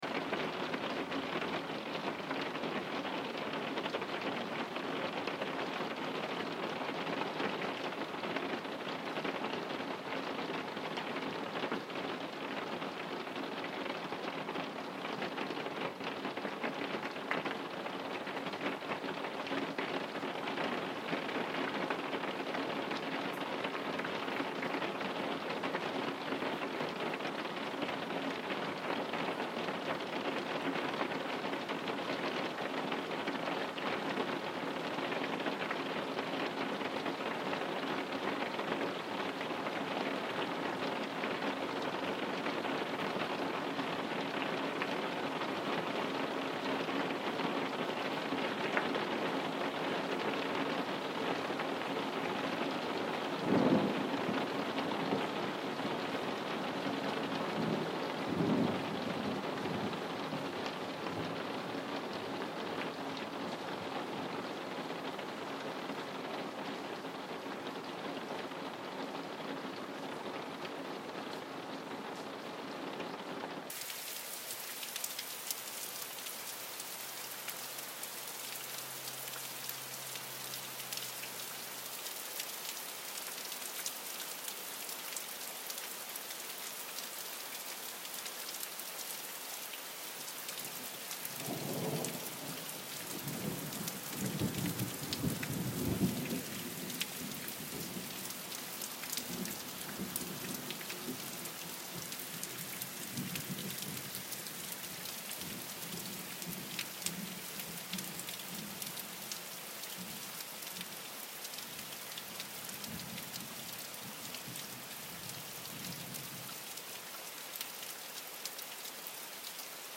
A storm on Christmas Day
Tropical storm in a country house next to the woods on December 25th, 2020 in Ouro Preto, Brazil.